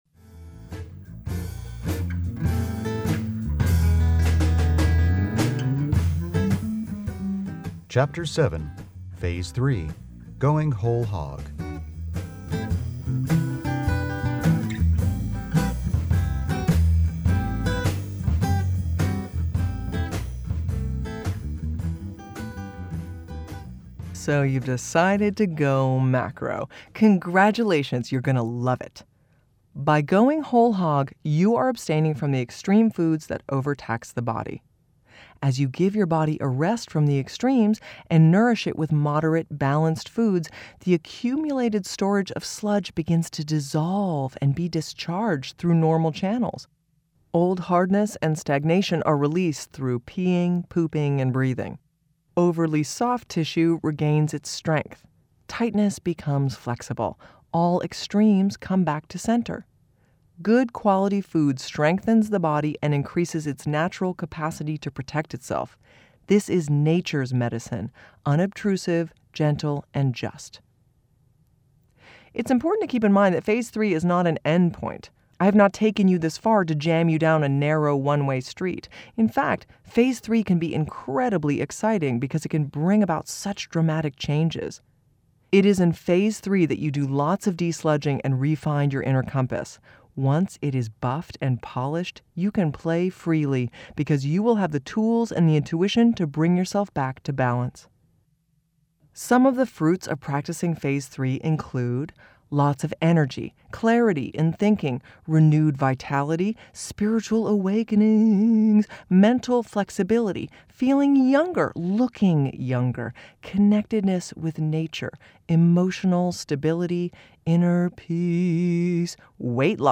We are very proud to announce the release of The Hip Chick’s Guide to Macrobiotics in audiobook form.